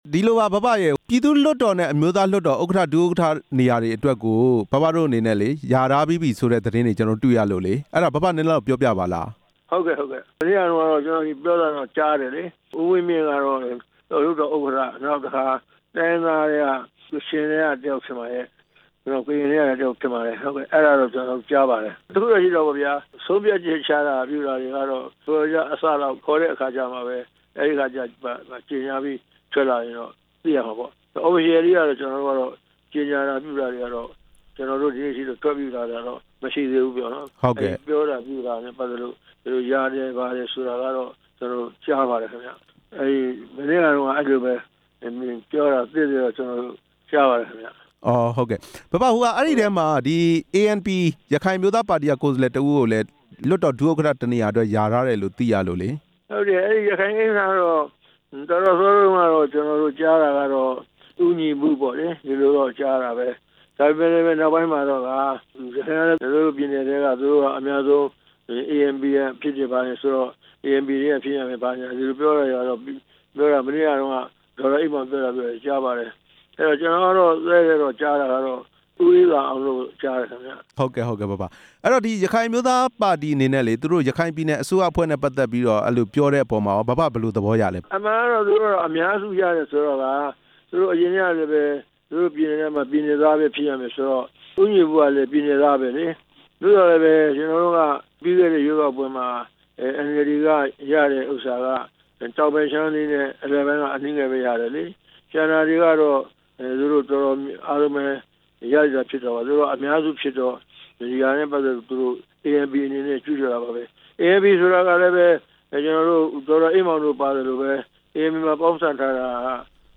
NLD နာယက ဦးတင်ဦးနဲ့ မေးမြန်းချက်